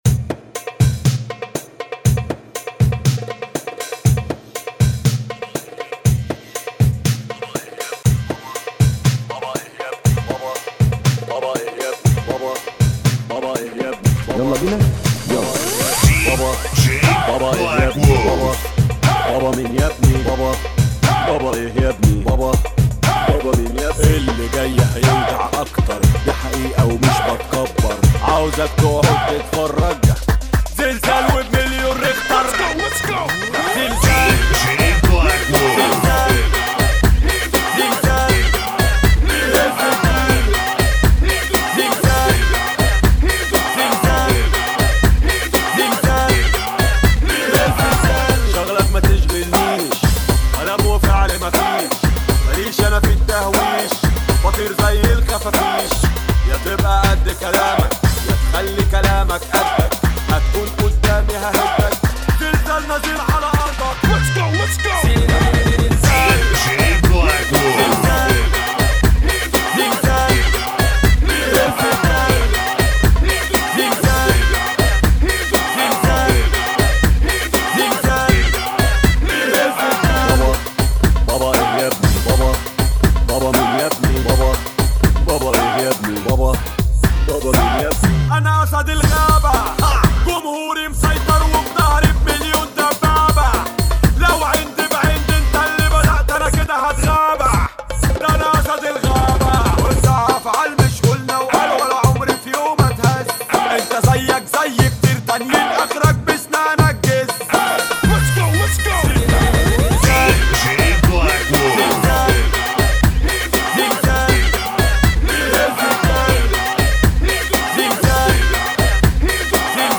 [ 120 Bpm ]